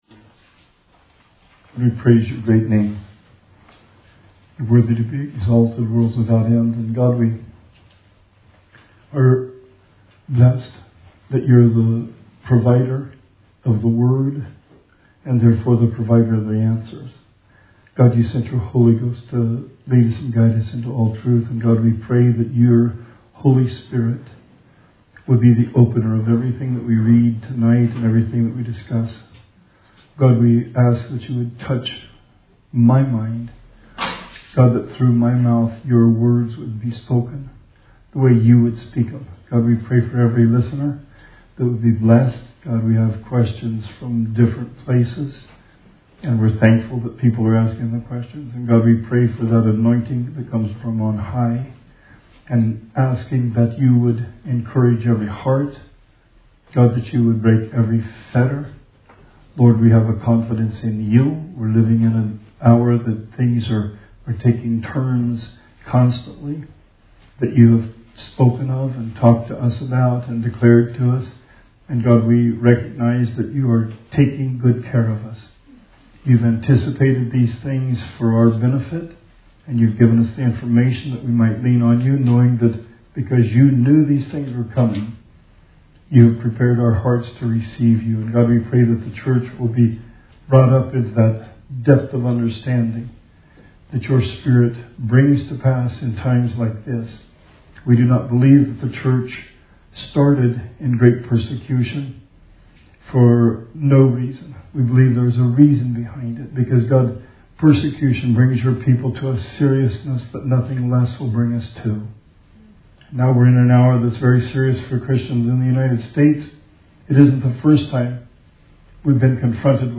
Bible Study 5/13/20